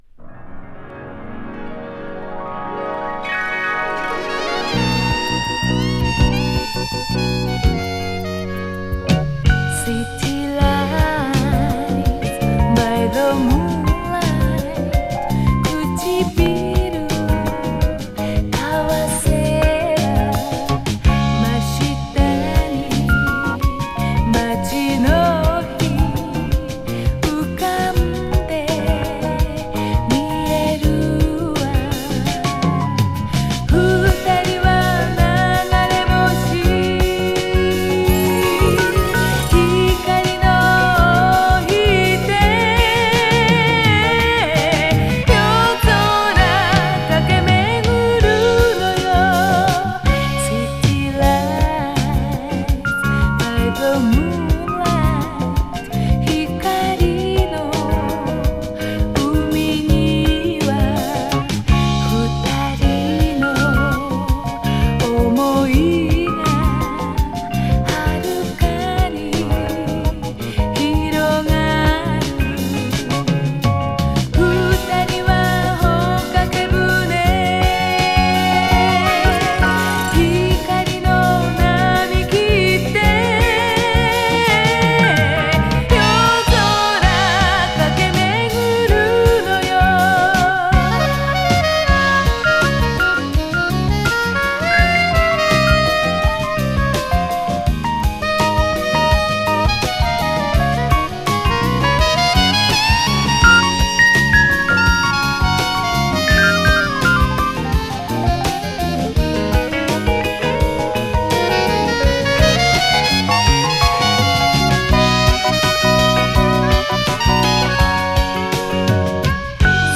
和物Light Mellow/AOR〜ジャパニーズ・シティ・ポップ名盤。